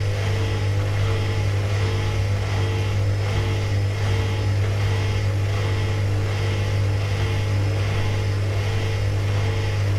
洗衣机 " 洗衣机清洗、漂洗、适度脱水
描述：洗衣机清洗，冲洗，中等旋转
标签： 漂洗 旋转 清洗
声道立体声